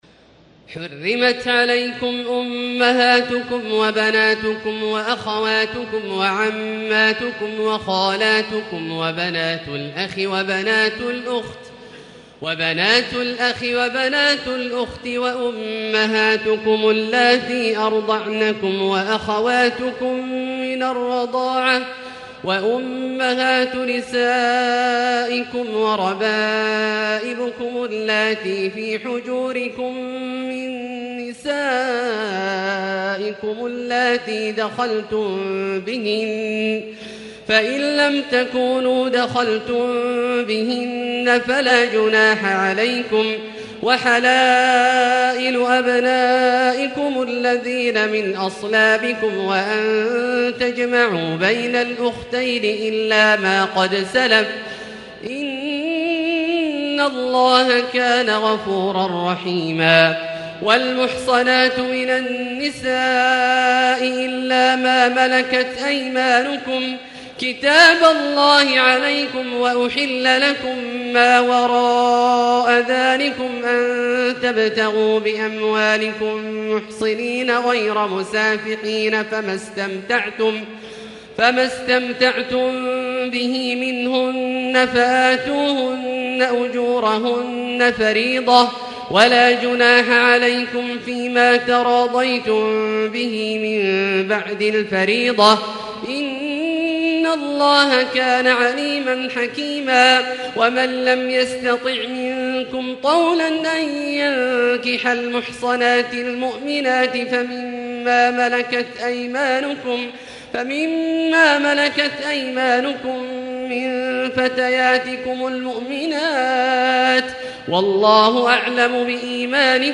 تراويح الليلة الرابعة رمضان 1440هـ من سورة النساء (23-87) Taraweeh 4 st night Ramadan 1440H from Surah An-Nisaa > تراويح الحرم المكي عام 1440 🕋 > التراويح - تلاوات الحرمين